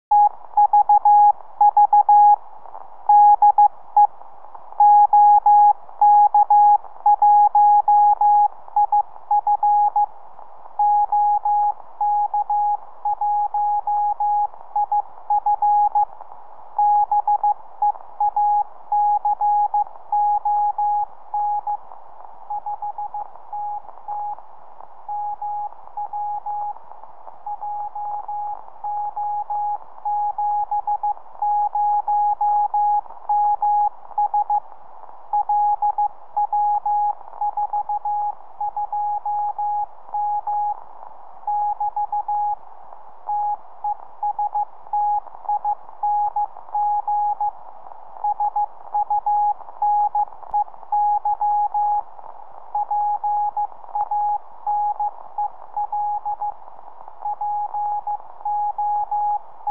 tvůj maják poslouchám velice dobře.
Na signálu jsou patrny úniky.